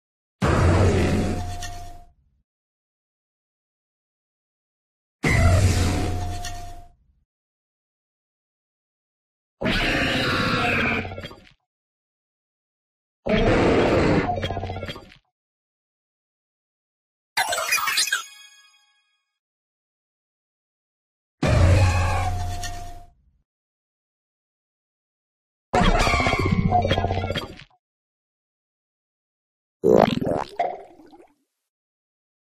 Cri d'Ire-Foudre dans Pokémon Écarlate et Violet.
contributions)On entendait la Zone Zéro en fond